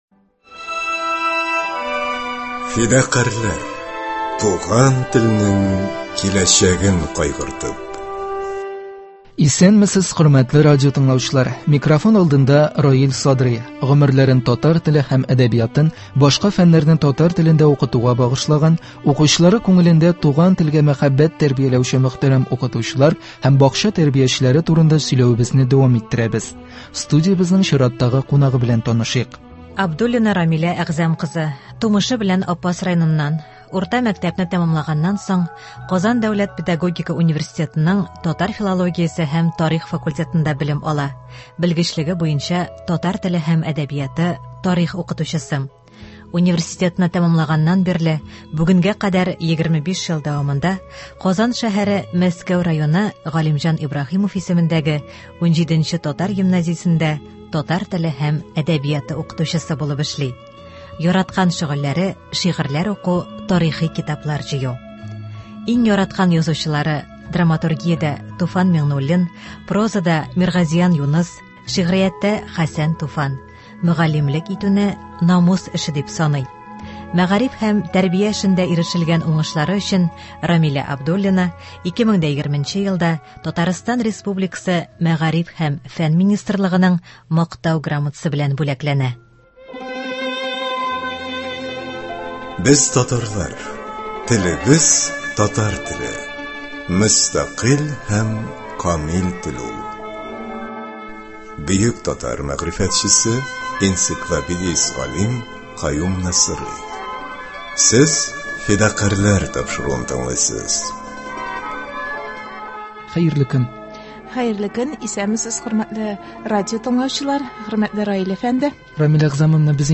Студиябезнең чираттагы кунагы